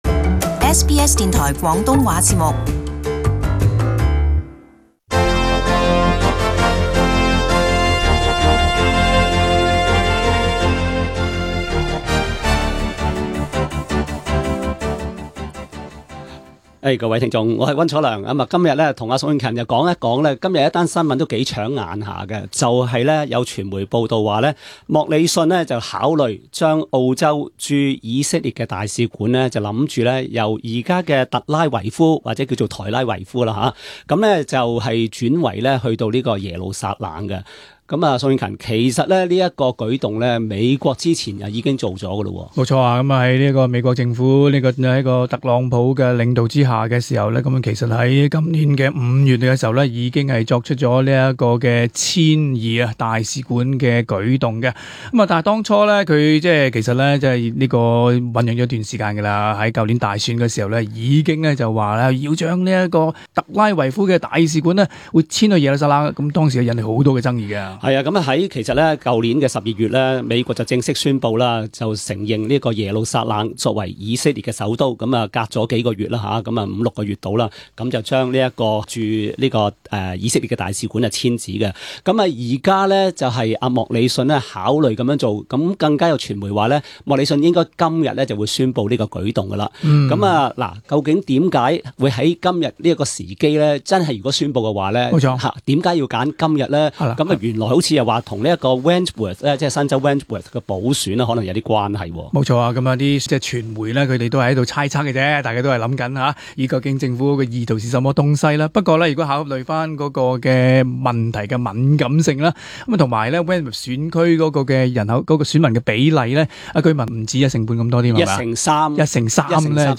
【時事報導】 工黨指莫里遜考慮駐以大使館遷耶路撒冷皆因選票